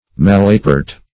malapert - definition of malapert - synonyms, pronunciation, spelling from Free Dictionary
malapert \mal"a*pert\ (m[a^]l"[.a]*p[~e]rt), a. [OF. malapert